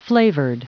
Prononciation du mot flavored en anglais (fichier audio)
Prononciation du mot : flavored